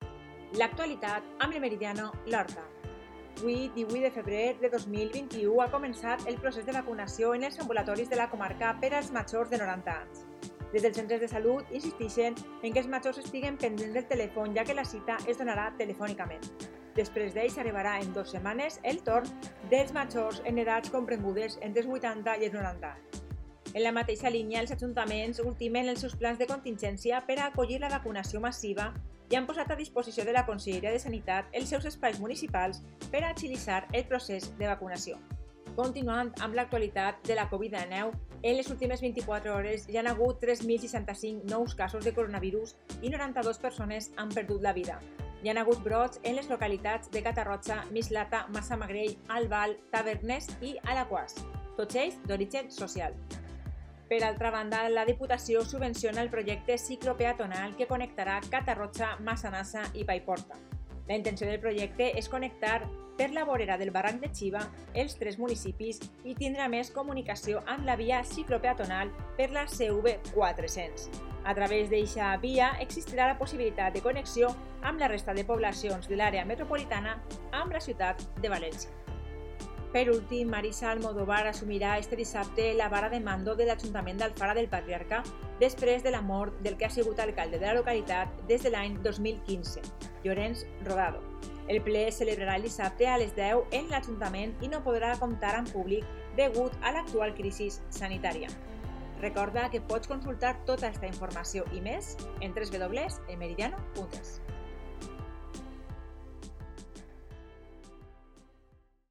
Informativo 18/2/21: